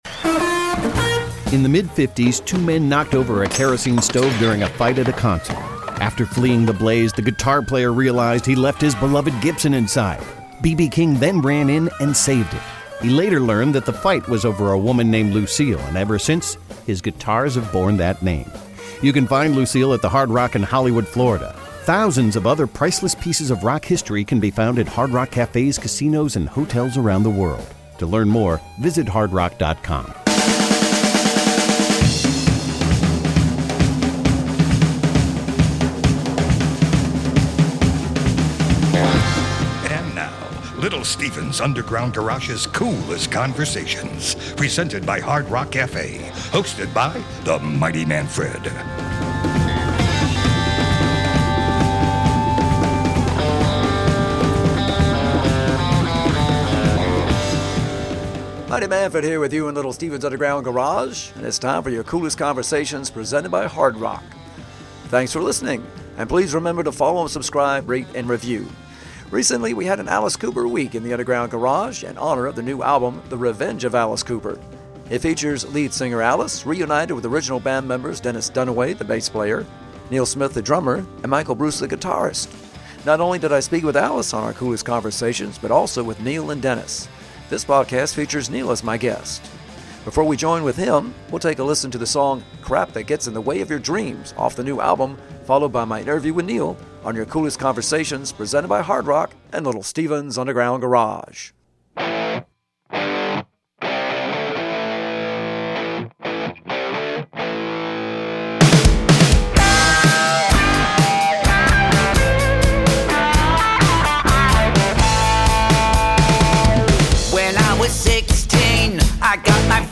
Celebrating Alice Cooper week in Little Steven's Underground Garage, we have a three part series with several of the original band member of Alice Cooper. This chat features drummer Neal Smith!